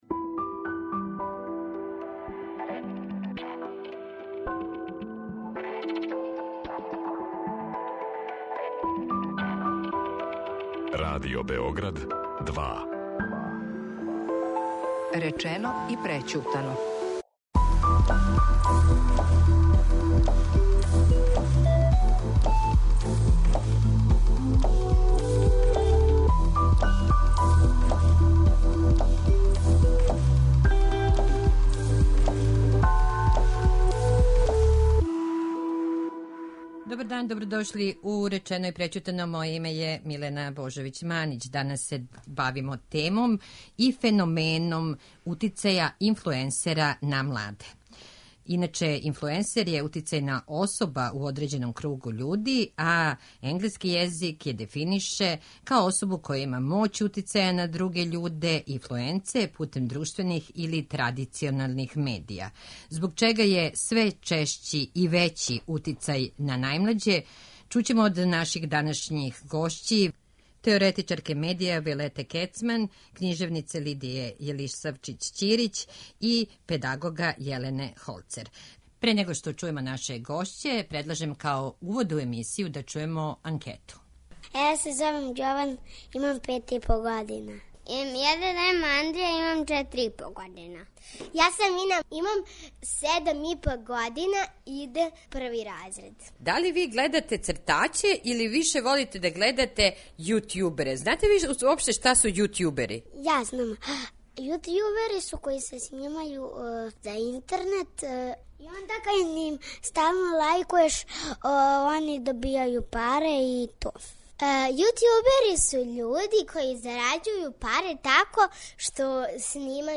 Чућемо и децу која у анкети објашњавају шта за њих значи јутјубер и колико их прате.